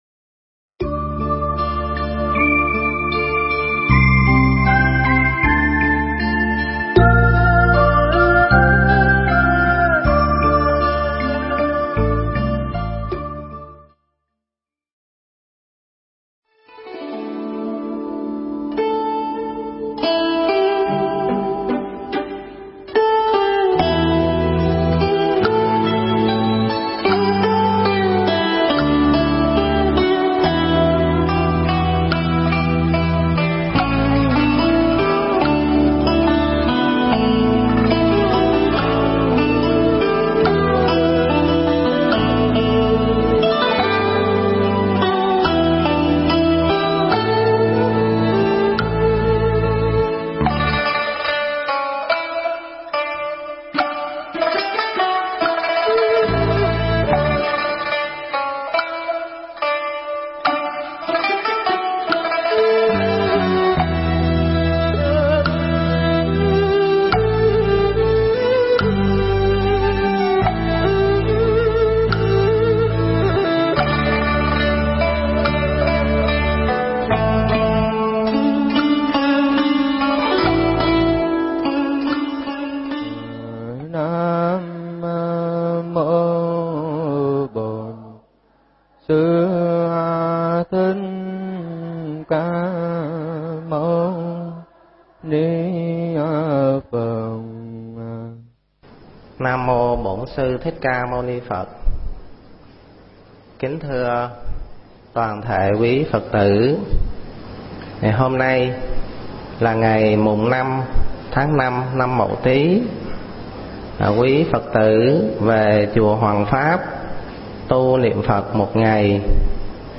Nghe Mp3 thuyết pháp Con Phật Con Ma
Mp3 pháp thoại Con Phật Con Ma